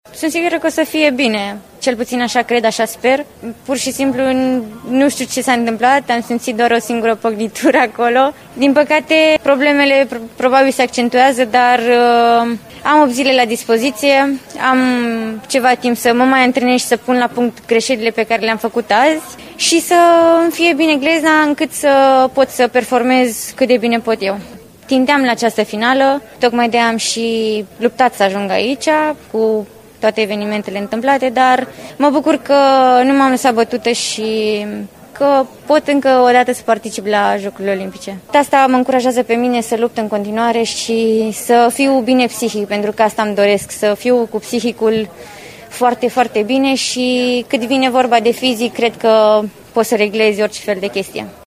Larisa Iordache a vorbit despre evoluția ei și despre condițiile în care trebuie să fie la momentul luptei pentru medalii: